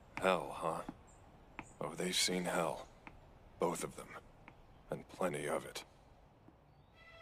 Small Dialogue Betwen State Alchemists 2.wav